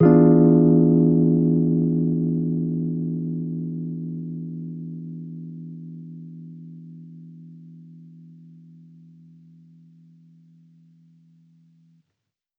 Index of /musicradar/jazz-keys-samples/Chord Hits/Electric Piano 1
JK_ElPiano1_Chord-E7b9.wav